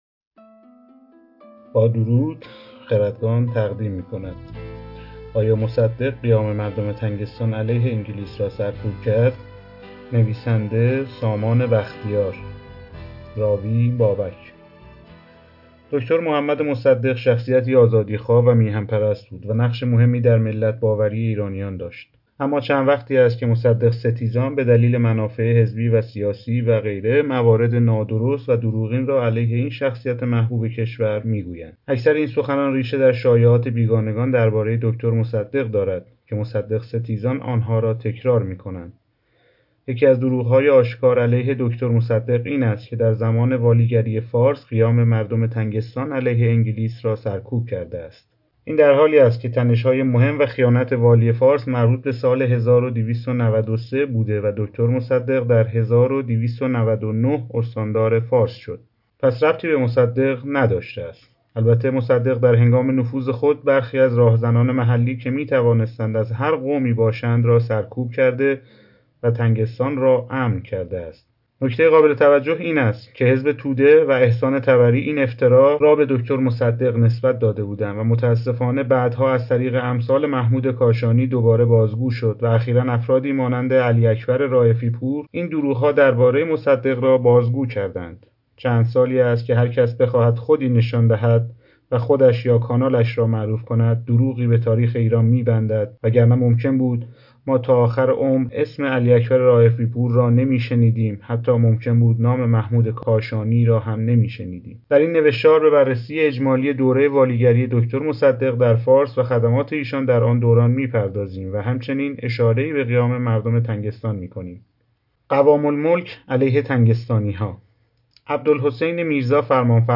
کتاب صوتی آیا مصدق قیام مردم تنگستان را سرکوب کرد؟ (پاسخ به شایعات)